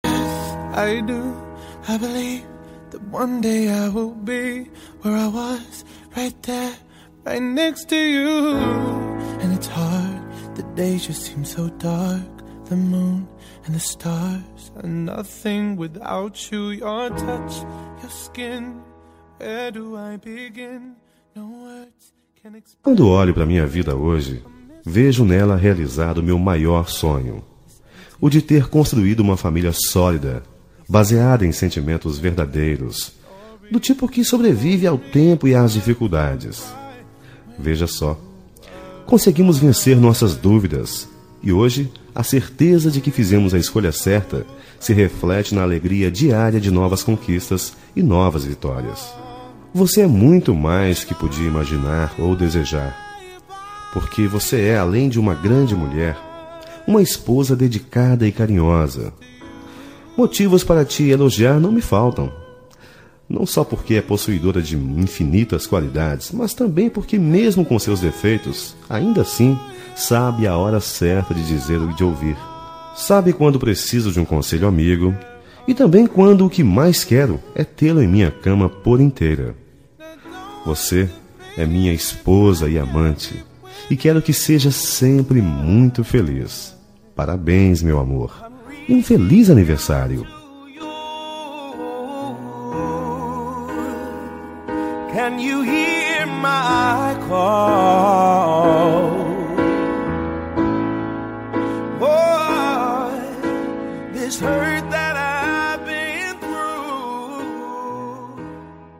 Aniversário Romântico – Voz Masculino – Cód: 350327